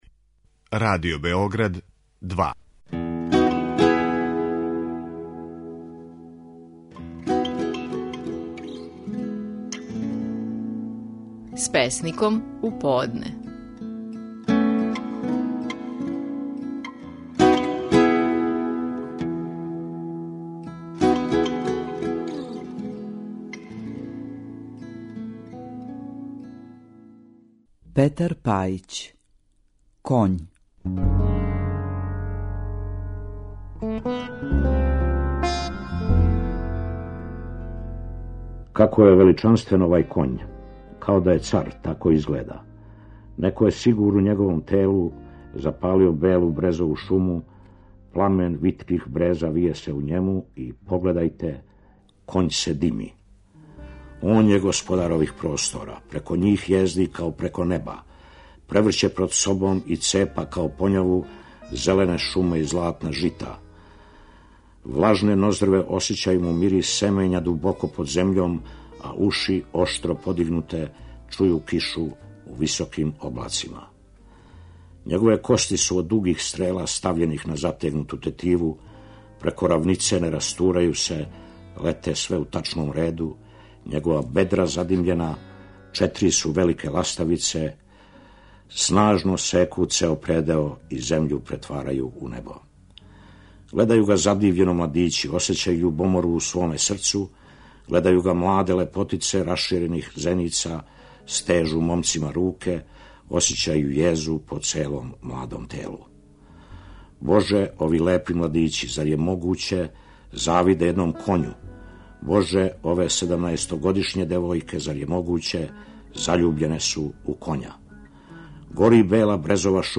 Наши најпознатији песници говоре своје стихове.
Данас слушамо Петра Пајића како говори стихове своје песме "Коњ".